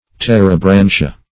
Search Result for " terebrantia" : The Collaborative International Dictionary of English v.0.48: Terebrantia \Ter`e*bran"ti*a\, n. pl.